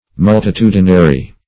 Meaning of multitudinary. multitudinary synonyms, pronunciation, spelling and more from Free Dictionary.
Multitudinary \Mul`ti*tu"di*na*ry\, a.